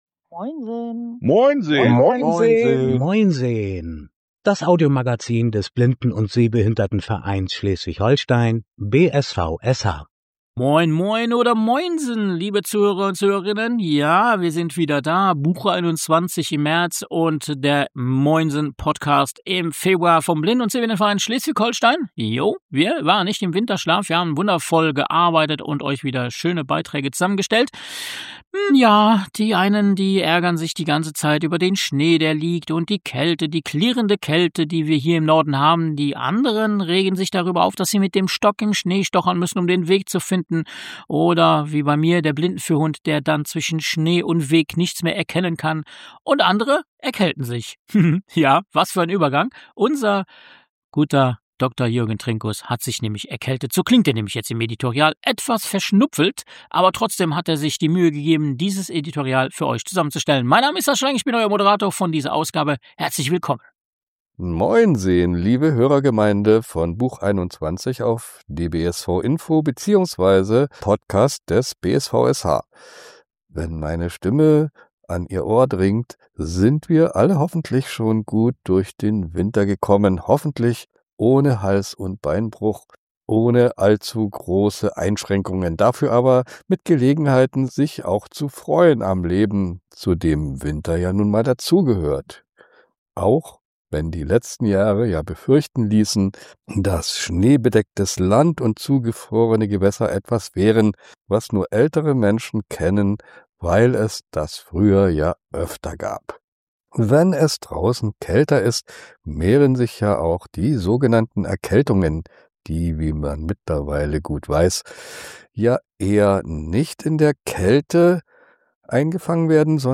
Ausgabe Februar 2026 Das Audiomagazin des BSVSH In der Februar 2026-Ausgabe von MoinSeHn